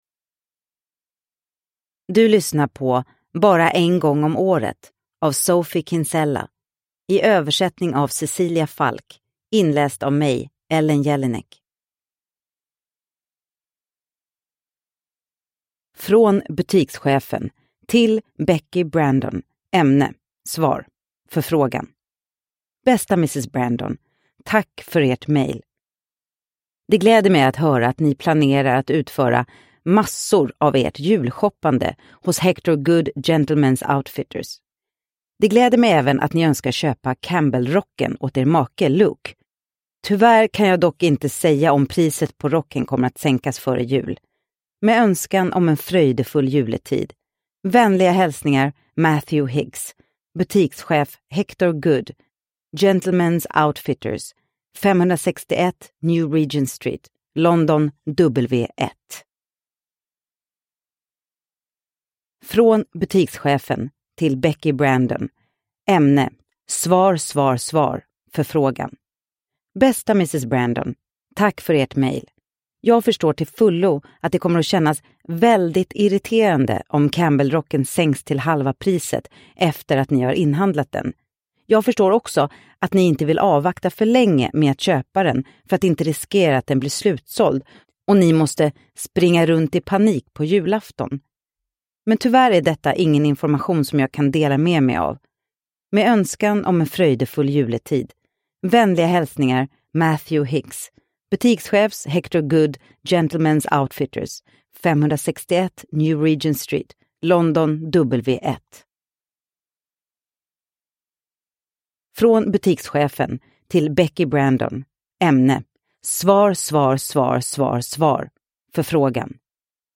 Bara en gång om året – Ljudbok – Laddas ner